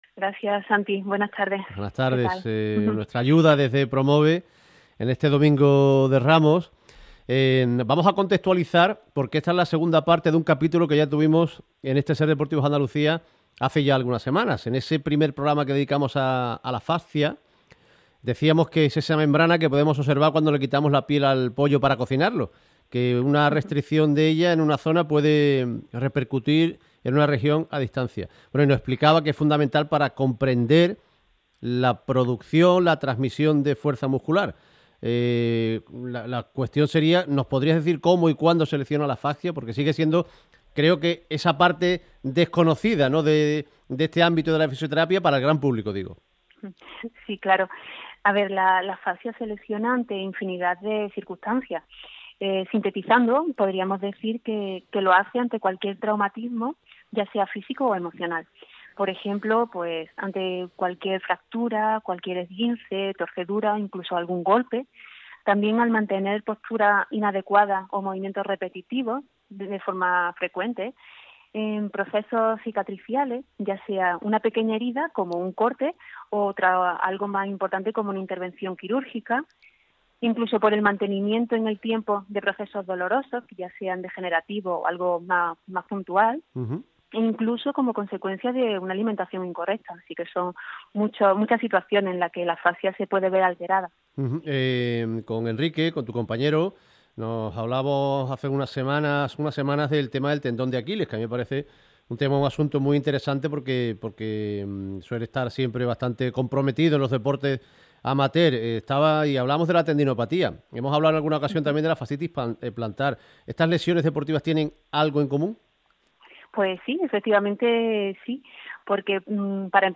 Fue en la Cadena SER, en el programa Ser Deportivos Andalucía.